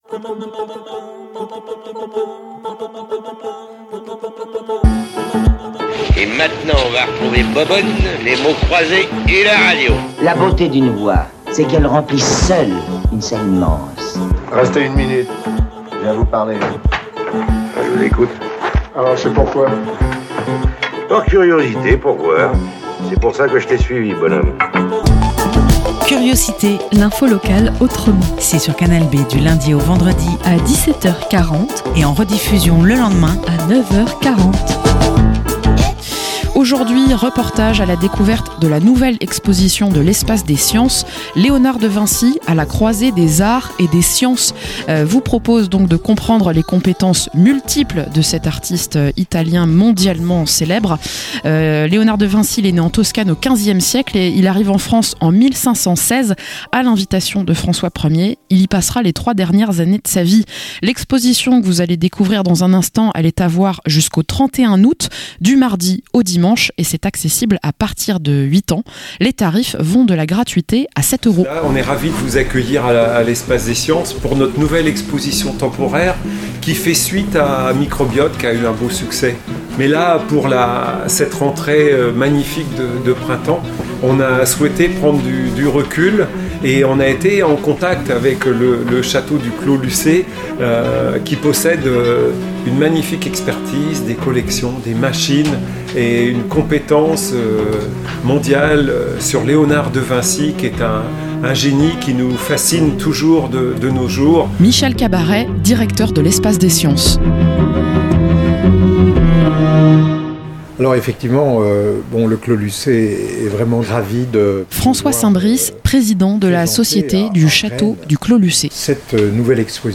- Reportage sur la nouvelle exposition de l'Espace des sciences autour de Léonard de Vinci.